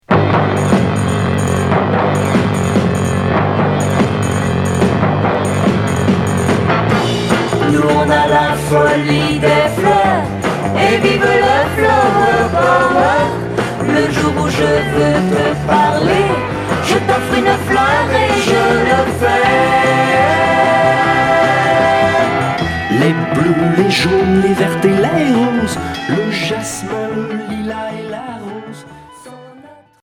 Pop 60's beat